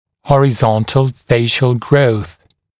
[ˌhɔrɪ’zɔntl ‘feɪʃl grəuθ][ˌхори’зонтл ‘фэйшл гроус]горизонтальный тип роста лица